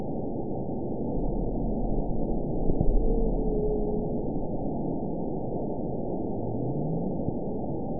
event 922142 date 12/27/24 time 09:50:46 GMT (11 months, 1 week ago) score 9.51 location TSS-AB04 detected by nrw target species NRW annotations +NRW Spectrogram: Frequency (kHz) vs. Time (s) audio not available .wav